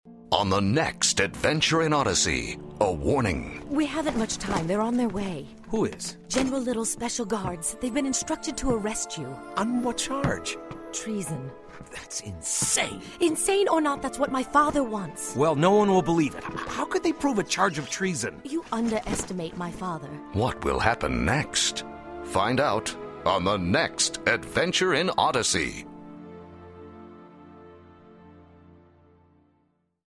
Notes: This audio dramatization is based on Darien's Rise from the Adventures in Odyssey Passages book series.